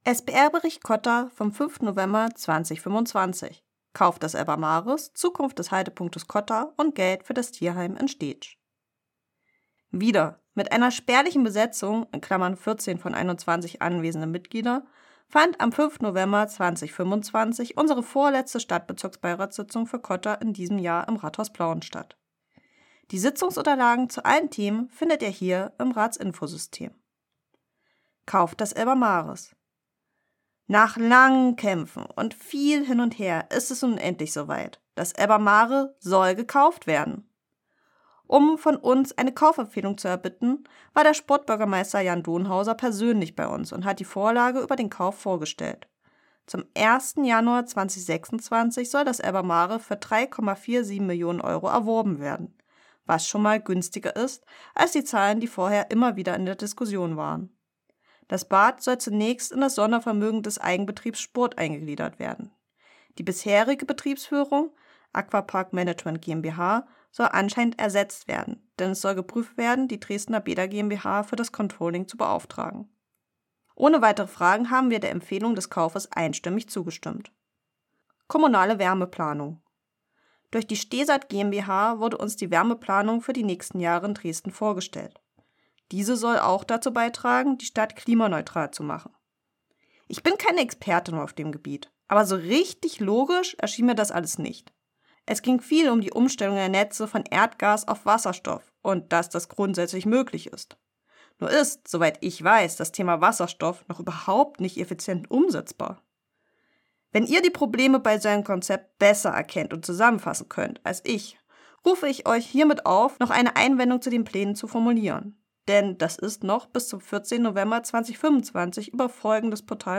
Stadtbezirksbeirätin für Cotta Stephanie Henkel berichtet über die Sitzung am 5. November 2025.